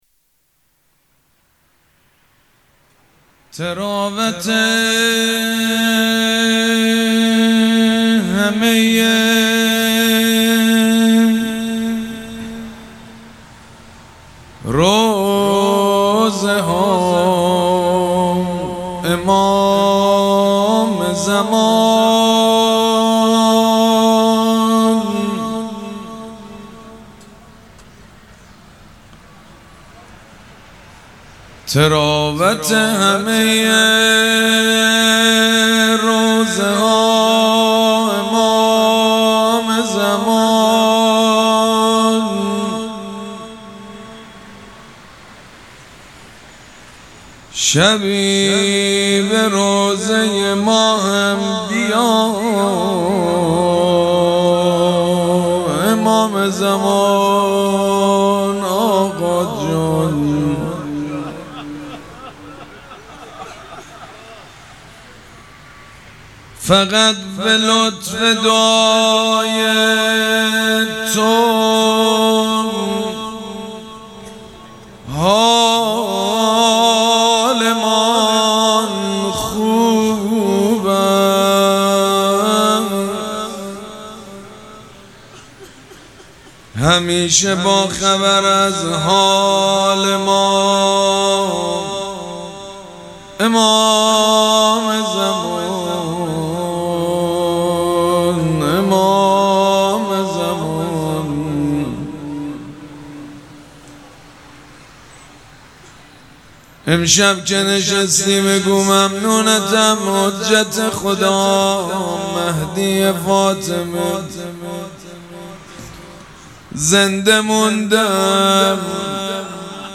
شب اول مراسم عزاداری اربعین حسینی ۱۴۴۷
شعر خوانی
مداح